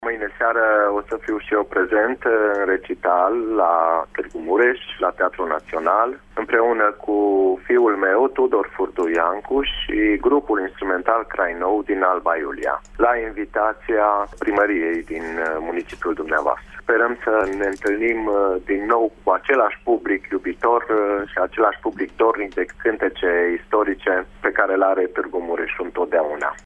Artistul a fost invitat să susţină un recital la Teatrul Naţional. Acesta vine la Tîrgu-Mureş alături de fiul său, a explicat, pentru Radio Tîrgu-Mureş, Nicolae Furdui Iancu: